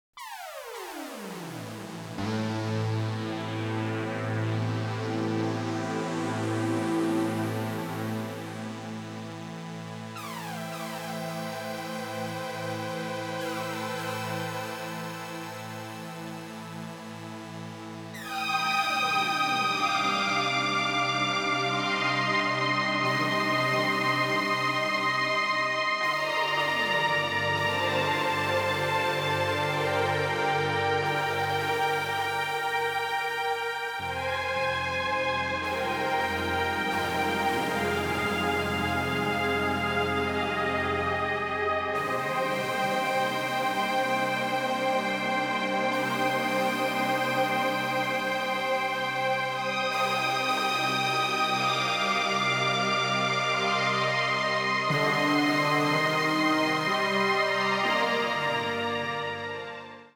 the usual eighties sound of synthesizers
a sort of new-age jazz mood
both are in pristine stereo sound.